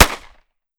9mm Micro Pistol - Gunshot B 005.wav